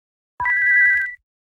Play, download and share psi attack(earthbound) original sound button!!!!